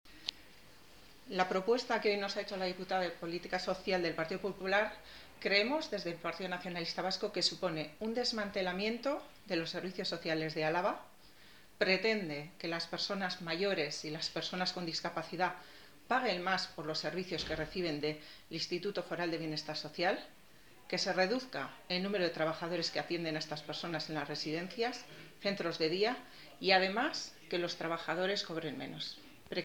Covadonga Solaguren, comparecencia Diputada de Servicios Sociales